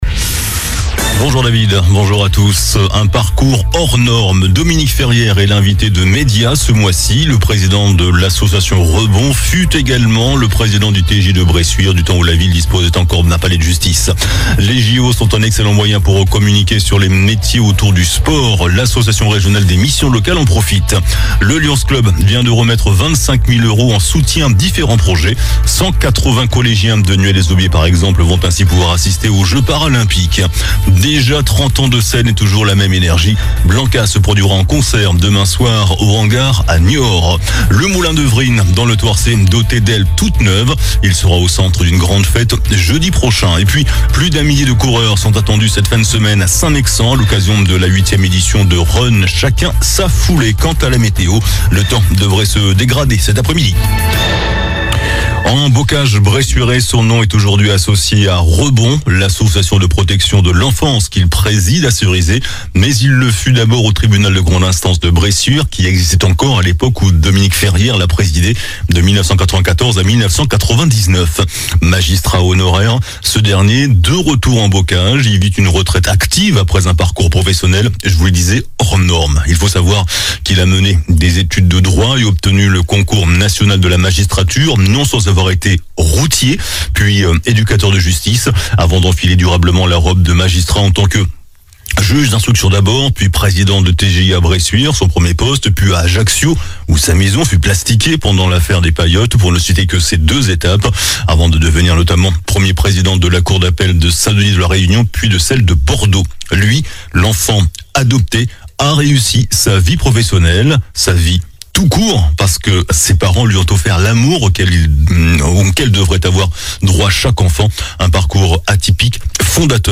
JOURNAL DU JEUDI 23 MAI ( MIDI )